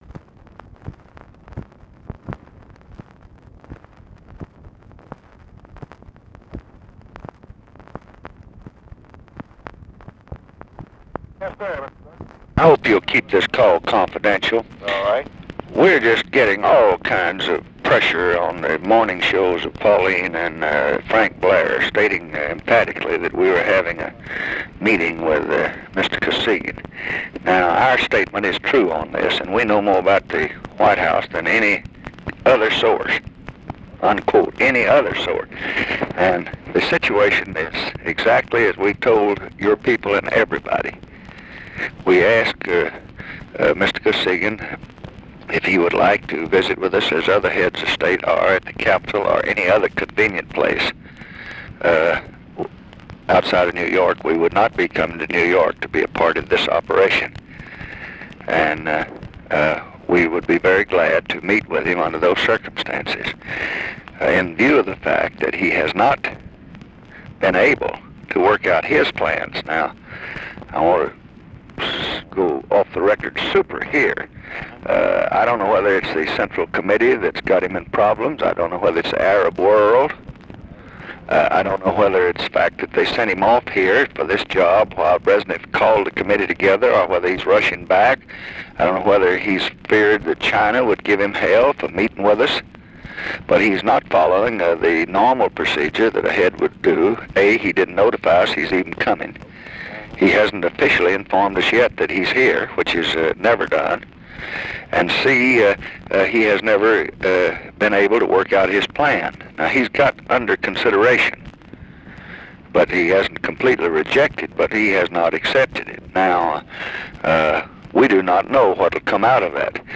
Conversation with JULIAN GOODMAN, June 21, 1967
Secret White House Tapes